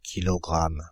Prononciation
PrononciationFrance (Île-de-France):
• IPA: /ki.lɔ.ɡʁam/